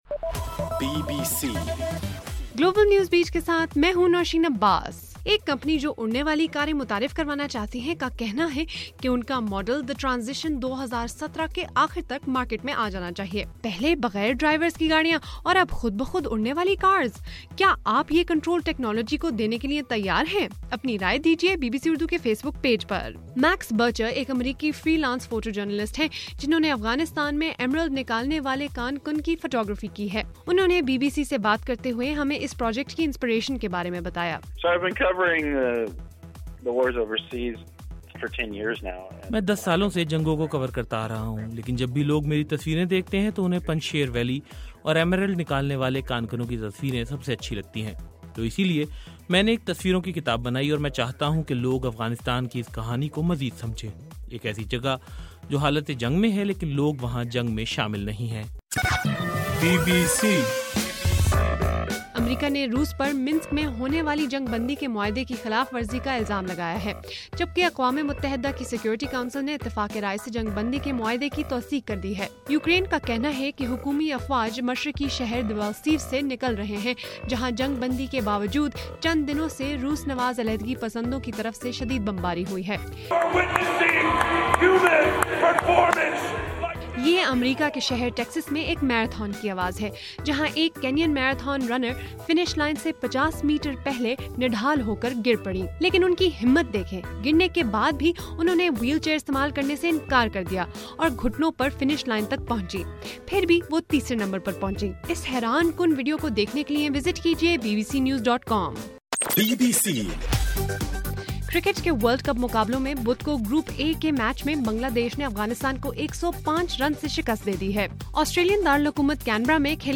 فروری 18: رات 9 بجے کا گلوبل نیوز بیٹ بُلیٹن